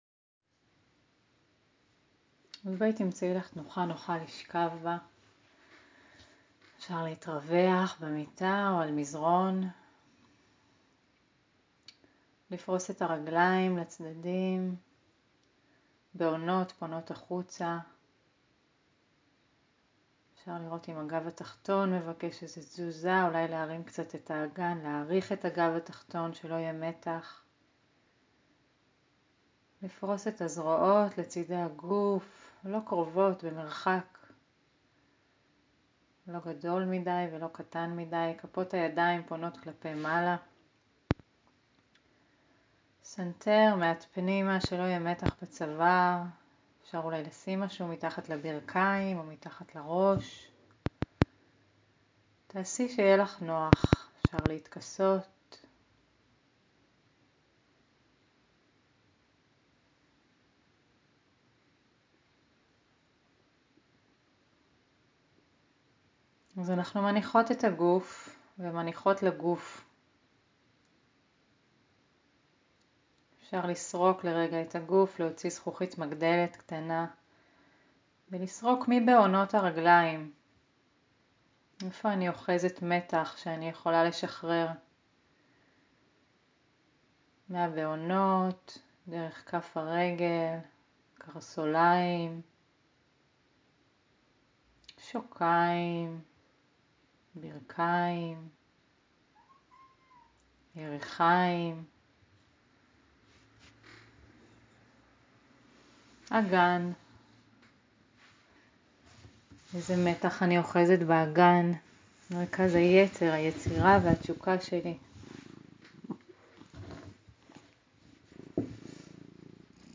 מדיטציה בשכיבה עם הזדמנות להרפות, לבדוק מה שלומי עכשיו ולאפשר להזנה עמוקה לחלחל פנימה 🙂